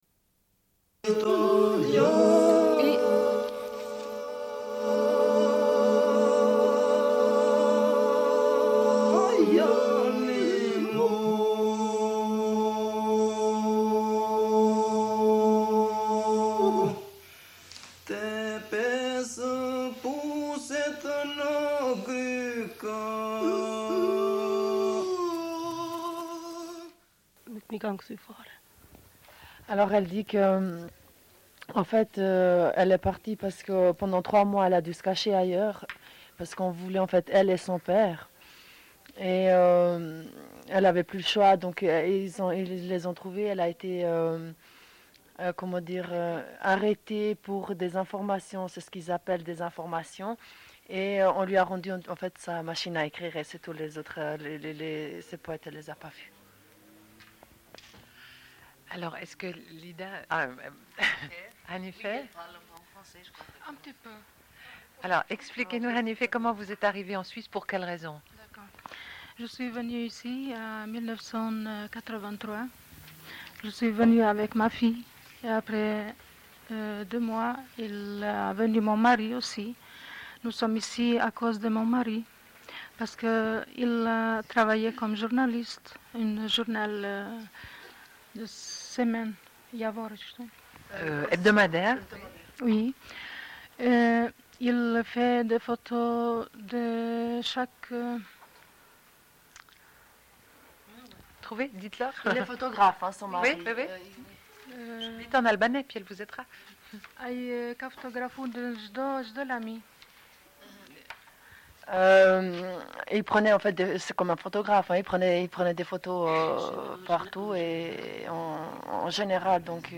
Suite de l'émission : rencontre avec cinq femmes kosovares, diffusion d'entretiens.